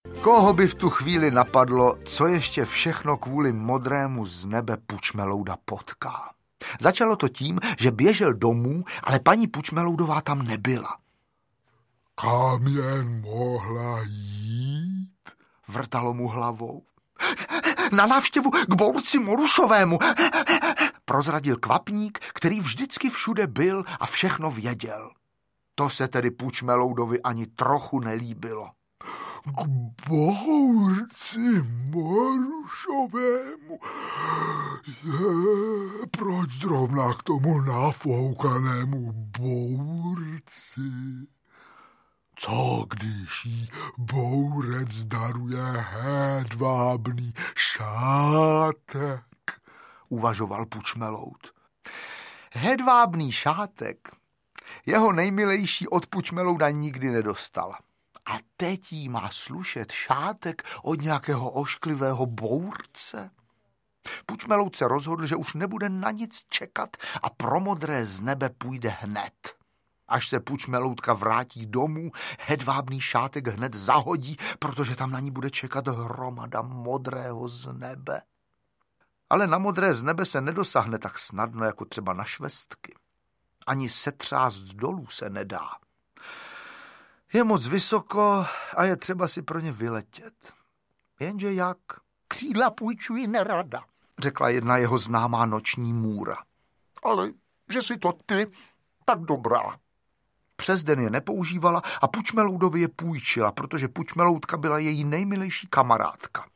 Audio kniha
Ukázka z knihy
• InterpretJosef Dvořák, Václav Vydra ml., Petr Skoumal